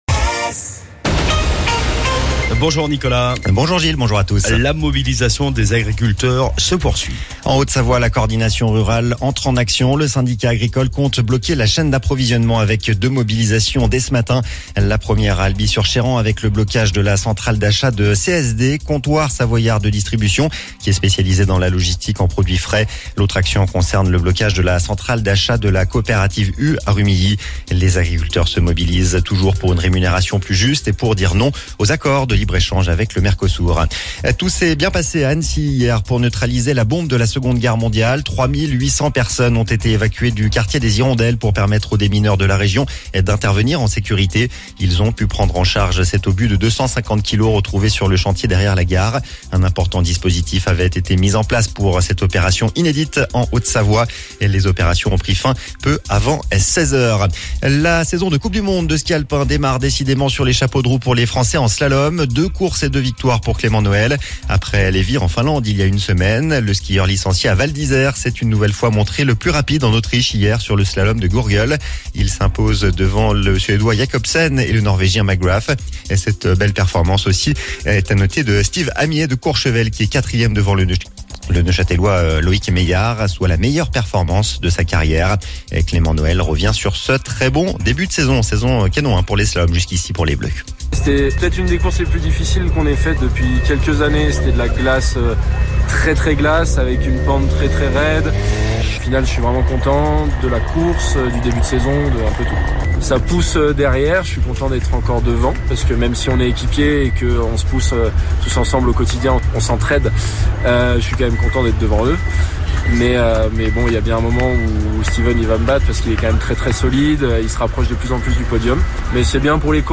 Le Flash Info, le journal d'ODS radio